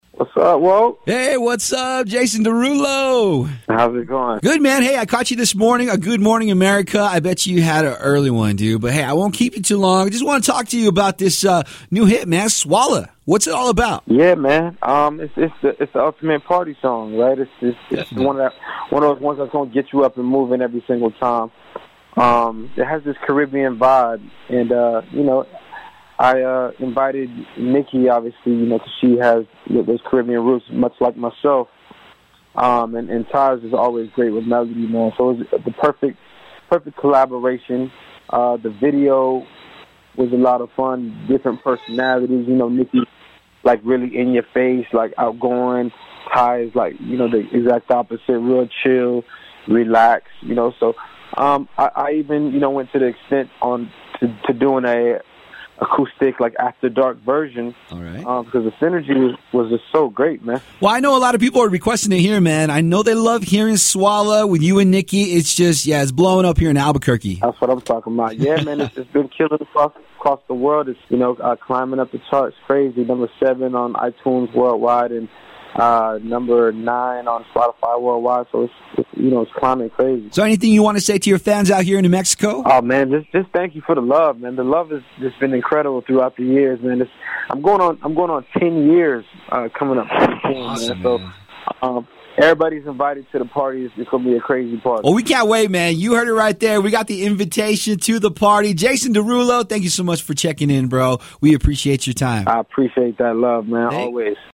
Jason DeRulo calls into the studio